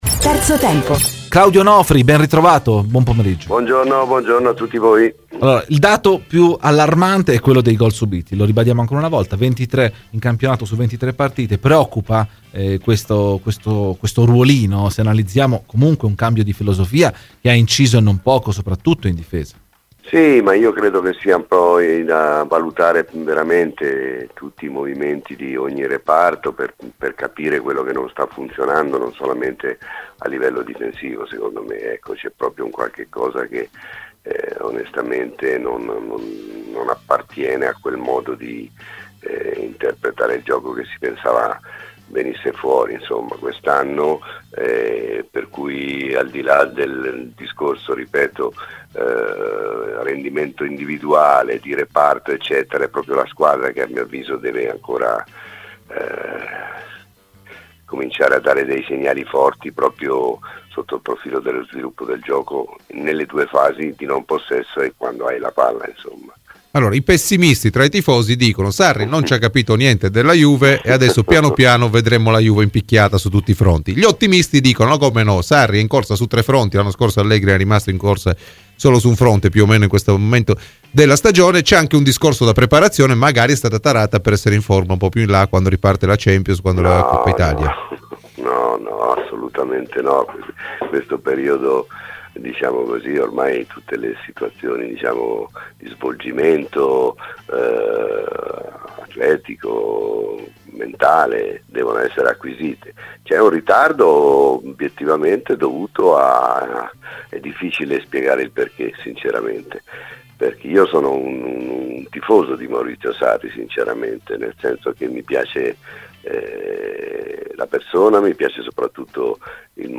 intervenuto ai microfoni di Radio Bianconera